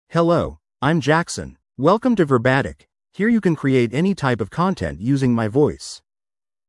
MaleEnglish (United States)
Jackson is a male AI voice for English (United States).
Voice sample
Male
Jackson delivers clear pronunciation with authentic United States English intonation, making your content sound professionally produced.